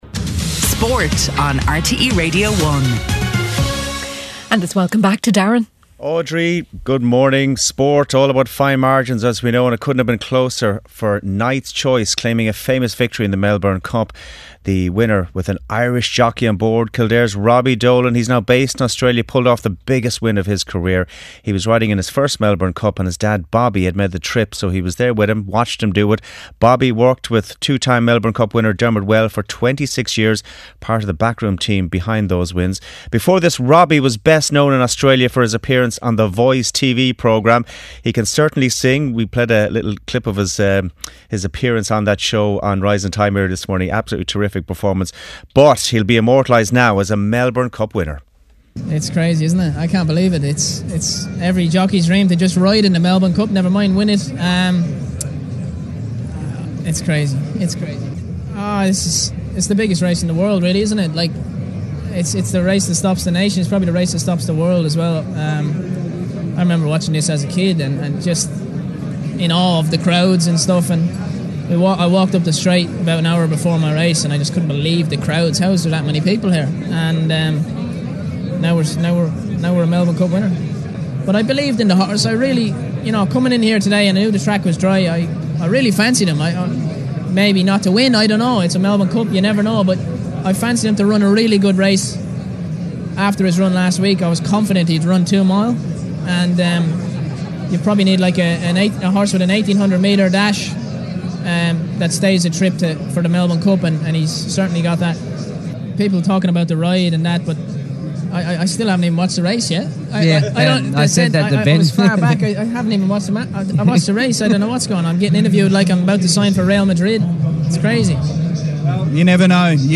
8:35am Sports News - 05.11.2024